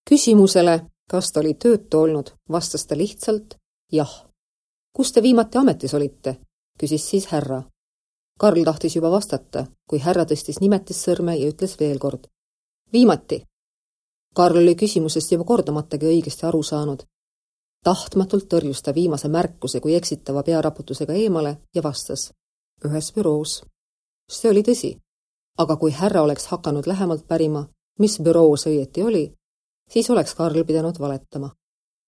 Estonian, Female, 20s-40s